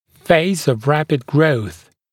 [feɪz əv ‘ræpɪd grəuθ][фэйз ов ‘рэпид гроус]фаза быстрого роста, период быстрого роста